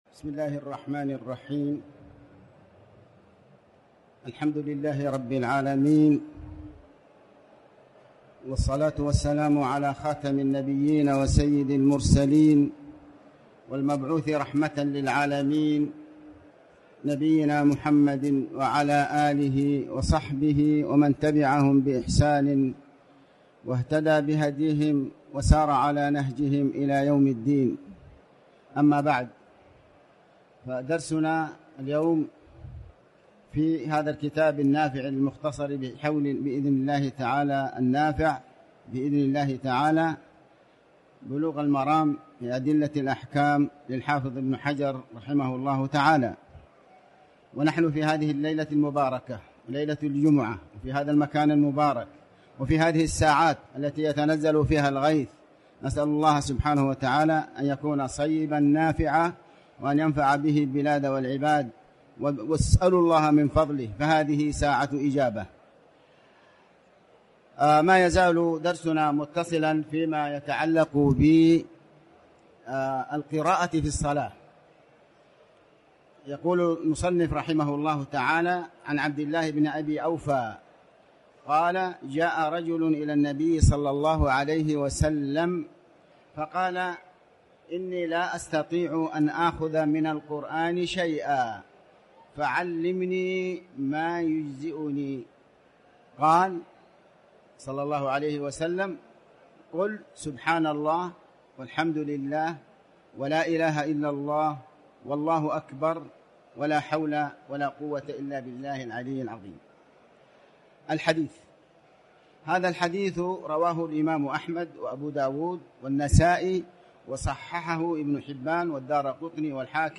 تاريخ النشر ١٧ محرم ١٤٤٠ هـ المكان: المسجد الحرام الشيخ: علي بن عباس الحكمي علي بن عباس الحكمي القراءة في الصلاة